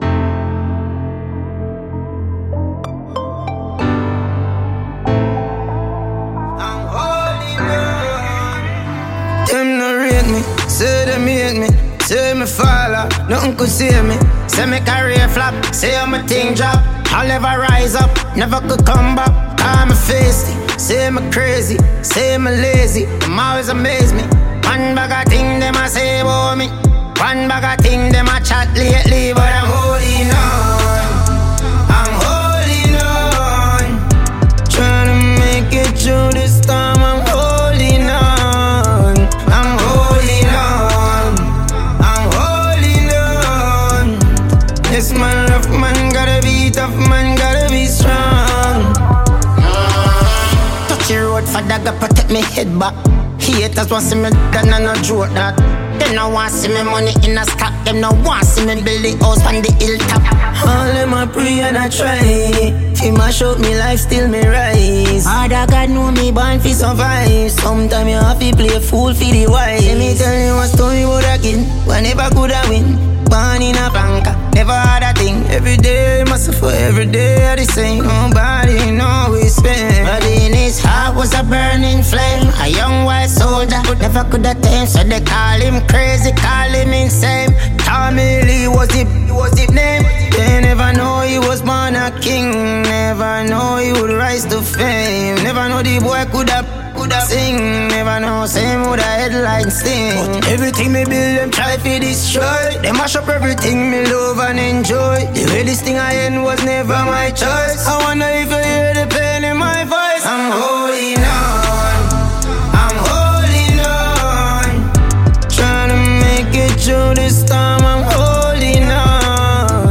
Dancehall
Jamaican Dancehall